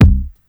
High Quality Smooth Bottom End Rap Français Kick Single Hit C Key 165.wav
Royality free bass drum sample tuned to the C note. Loudest frequency: 208Hz
.WAV .MP3 .OGG 0:00 / 0:01 Type Wav Duration 0:01 Size 85,63 KB Samplerate 44100 Hz Bitdepth 16 Channels Stereo Royality free bass drum sample tuned to the C note.